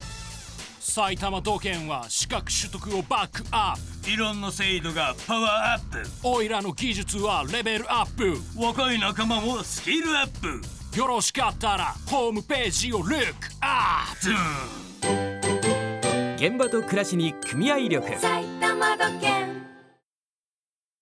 埼玉土建ラジオCMアーカイブ